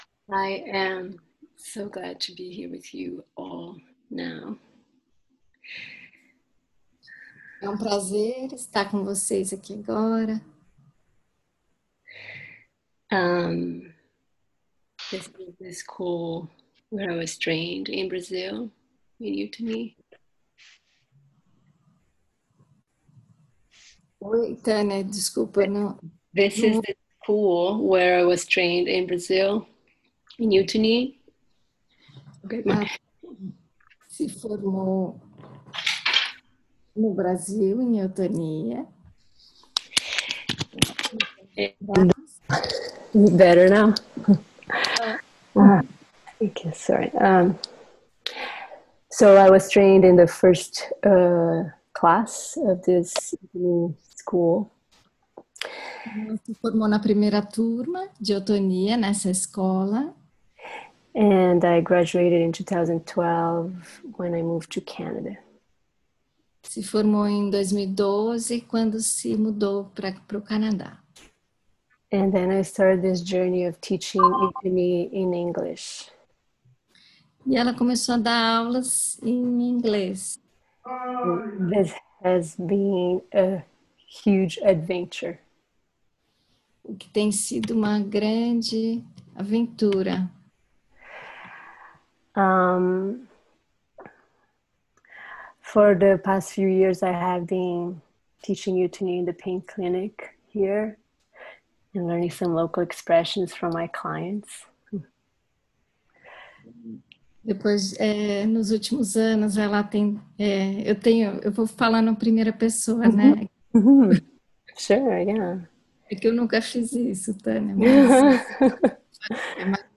Here is the introduction to the practice and below you can access a link to an audio of the entire session - both in English and Portuguese.